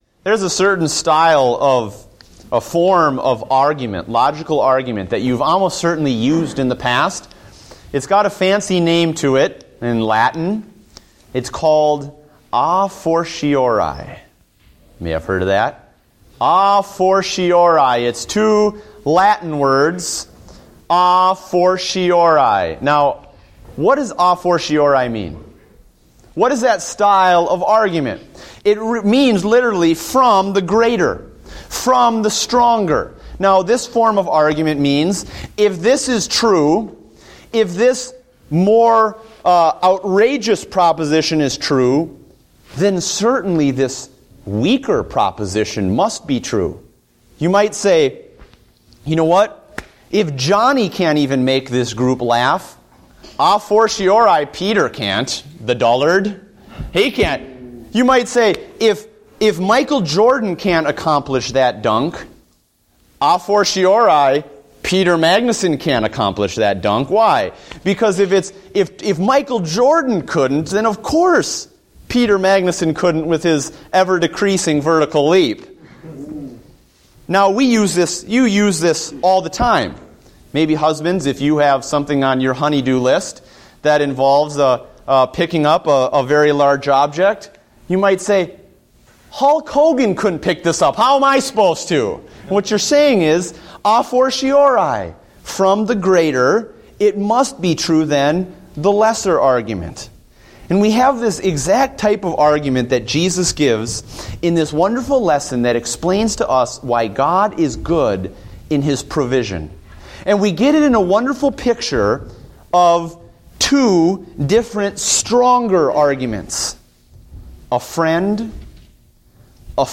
Date: September 14, 2014 (Adult Sunday School)